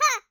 Sfx Player Boulder Toss Sound Effect
sfx-player-boulder-toss-1.mp3